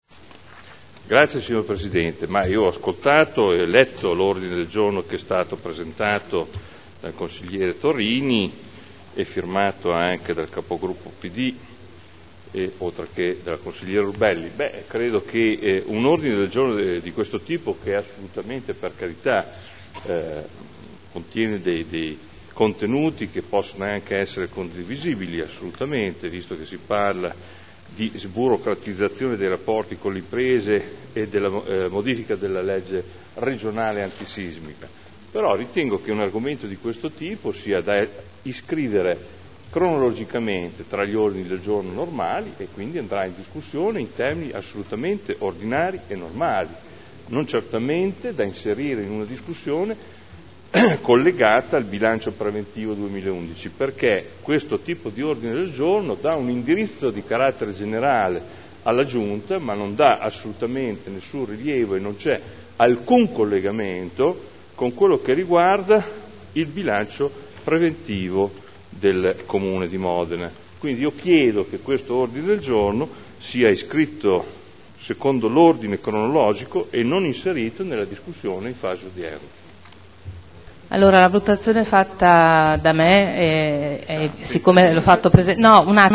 Adolfo Morandi — Sito Audio Consiglio Comunale